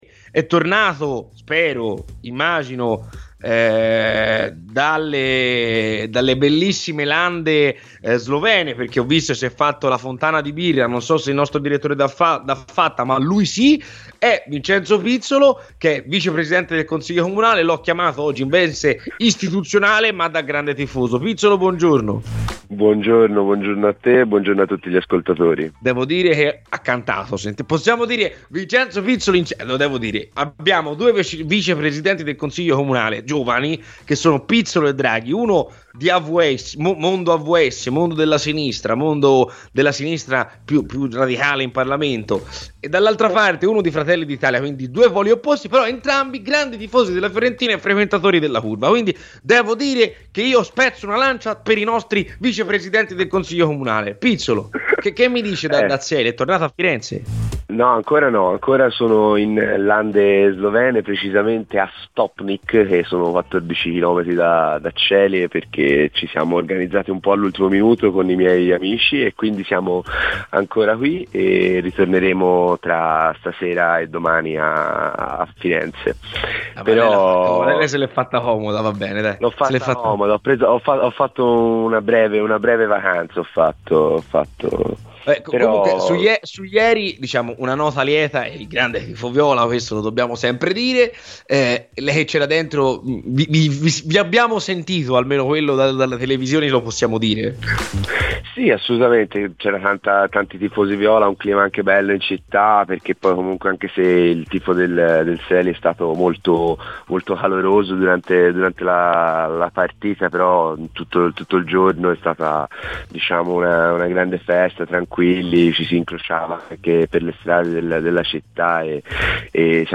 Il Vicepresidente del Consiglio Comunale di Firenze e grande tifoso gigliato Vincenzo Pizzolo è intervenuto ai microfoni di Radio FirenzeViola durante la trasmissione "C'è polemica" per parlare dell'attualità di casa Fiorentina tra campo e politica.